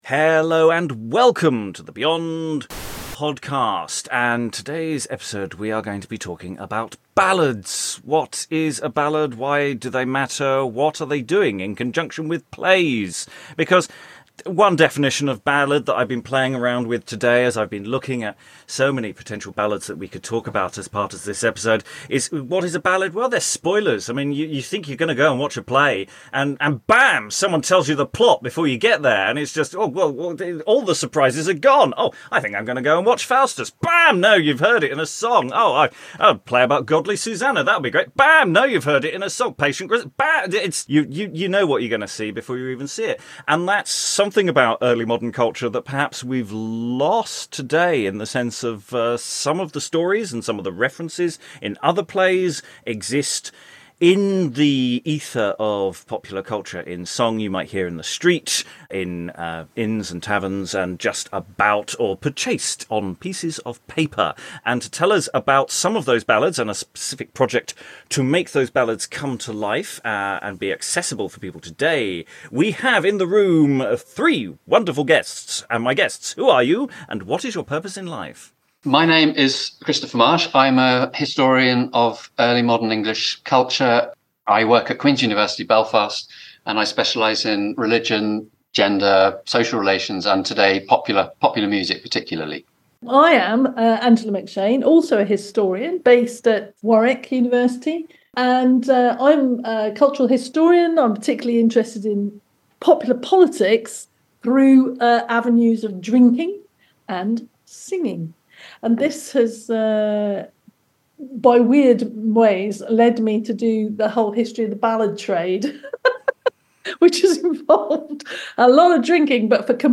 This is a very late release of a discussion we had last year about the website 100 Ballads, which features over 100 ballads from the early modern period.